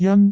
speech
syllable
pronunciation
jan1.wav